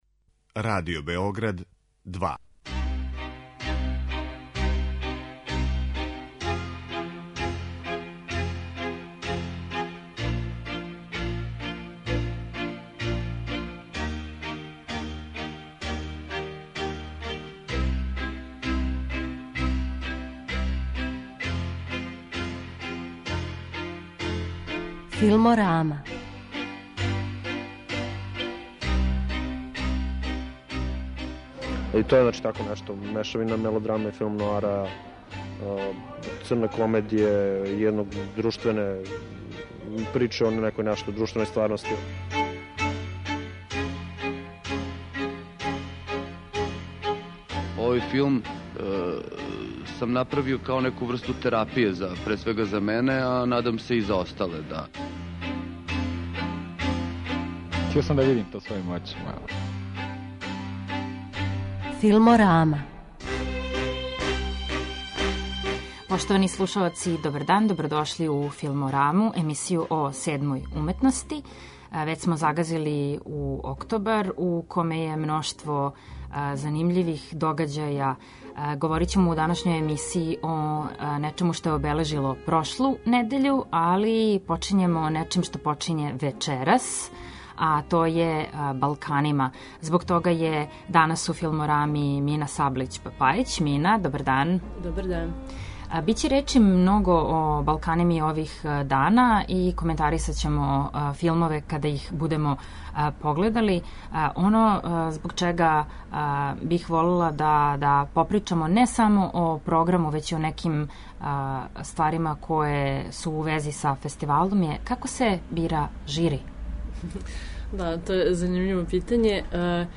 Емисија о филму
Чућете звучну забелешку са овог догађаја.